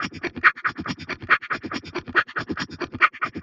tx_perc_140_intensity2.wav